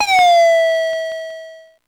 Index of /90_sSampleCDs/300 Drum Machines/Electro-Harmonix Spacedrum
Drum08.wav